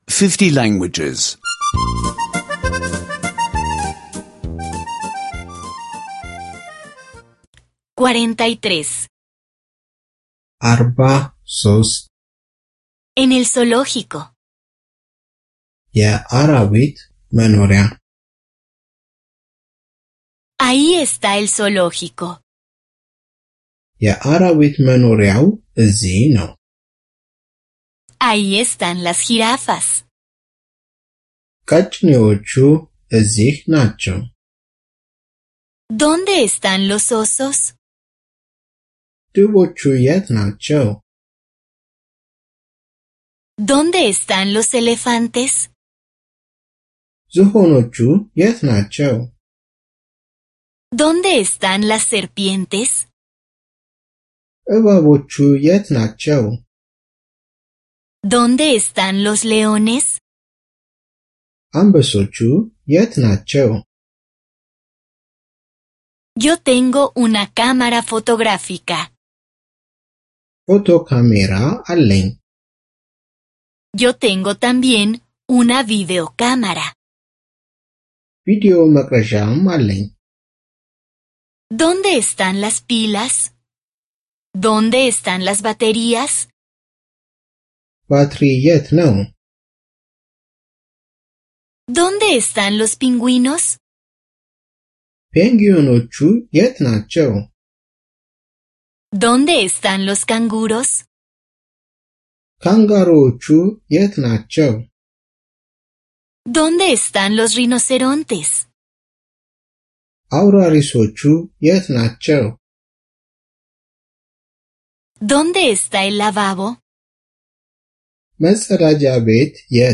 Curso de audio de amárico (escuchar en línea)